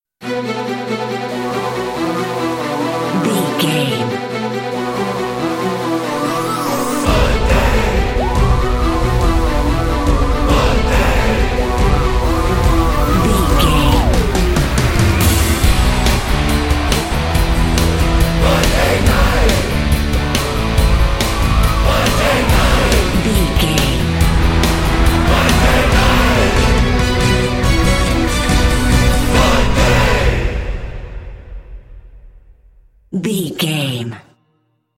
Epic / Action
Aeolian/Minor
strings
drums
cello
violin
percussion
orchestral hybrid
dubstep
aggressive
energetic
intense
powerful
bass
synth effects
wobbles
heroic
driving drum beat
epic